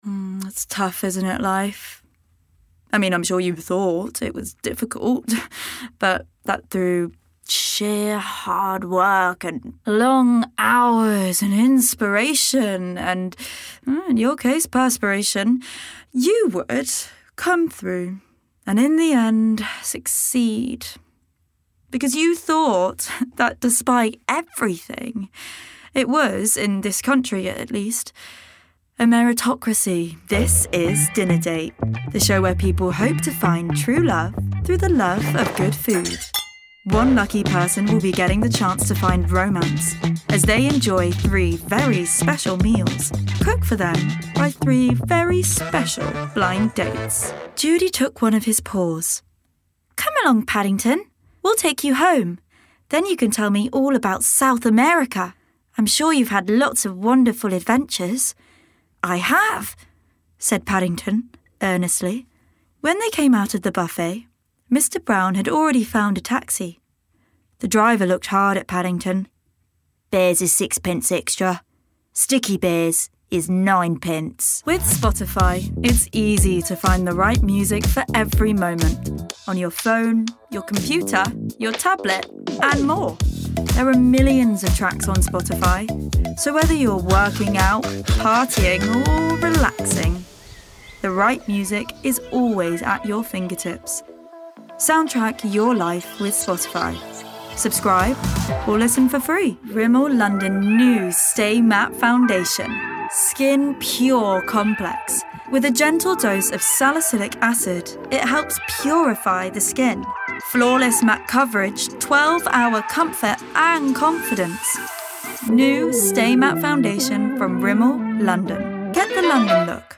Native voice:
Contemporary RP
Voicereel: